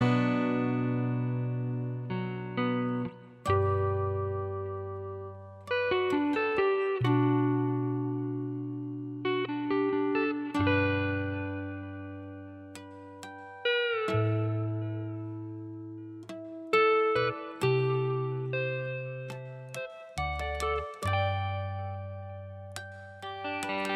Minus 12 String Guitar Rock 5:39 Buy £1.50